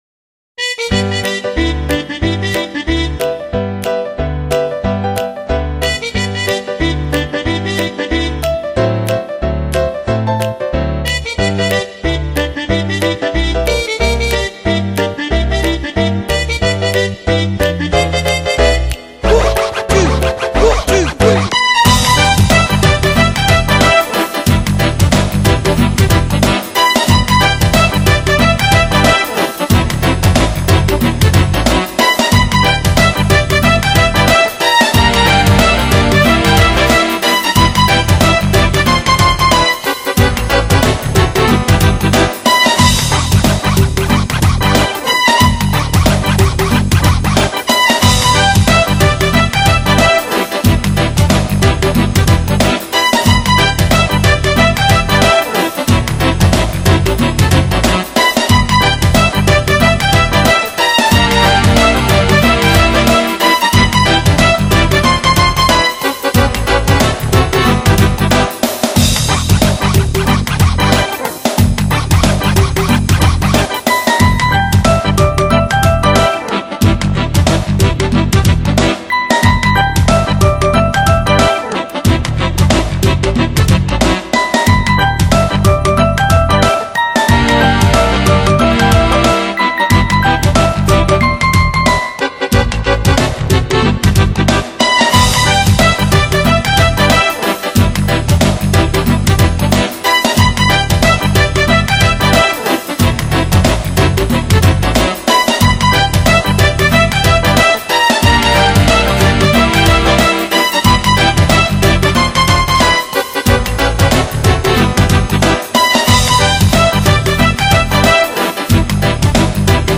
【音乐类型】：纯音乐专辑5CD
门金曲，旋律性强而富有动感，散发着无穷的时尚气息。